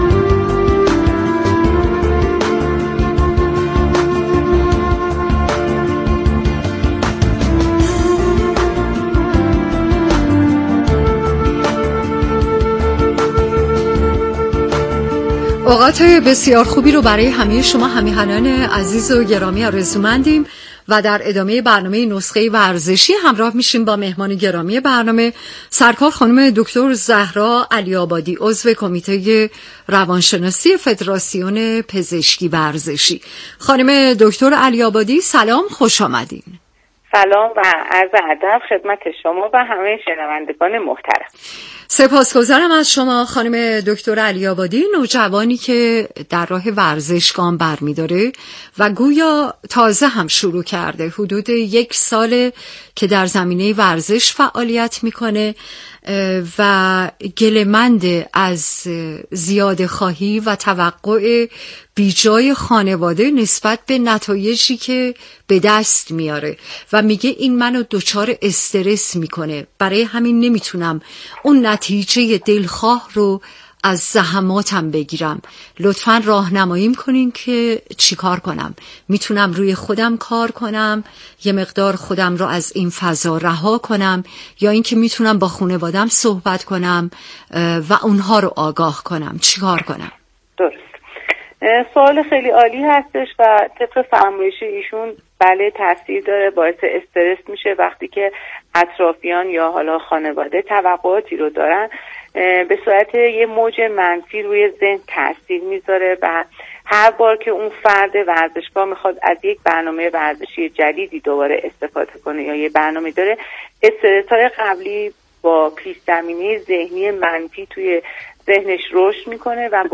در گفت و گو با رادیو ورزش تبیین شد؛